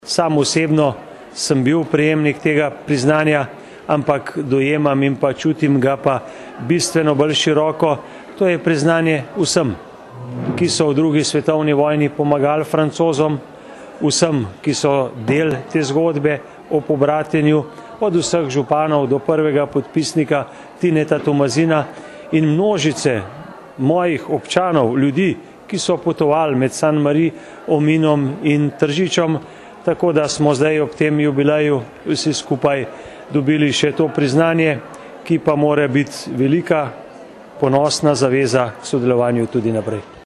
V čustvenem nagovoru po prejemu visokega francoskega odlikovanja, se je župan Občine Tržič mag. Borut Sajovic zahvalil vsem, ki so zaslužni zanj.
76743_izjavazupanaobcinetrzicmag.borutasajovicaobprejemufrancoskegaodlikovanja.mp3